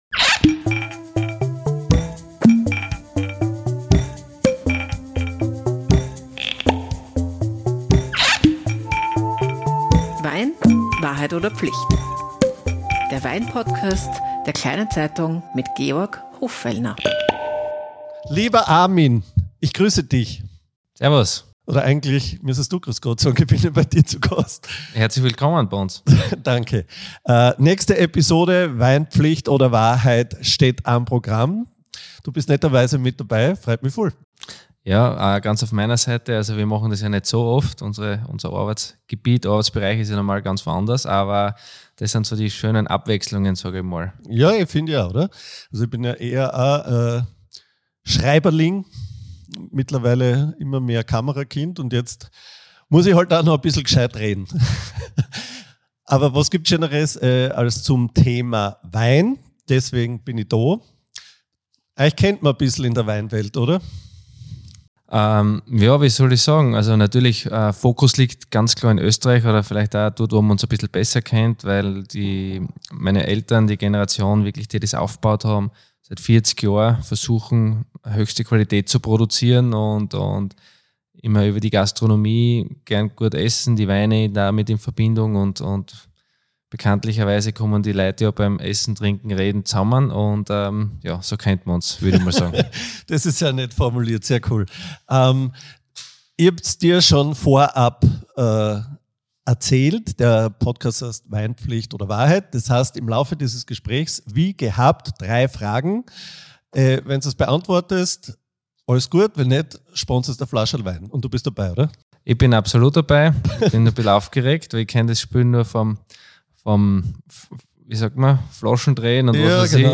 Und genau so "real" ist auch das Gespräch – zwischen Kaffee, Baustellenlärm und Sauvignon Blanc. Über ein Leben zwischen Weinberg und WLAN.